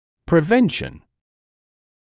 [prɪ’venʃn][при’вэншн]предотвращение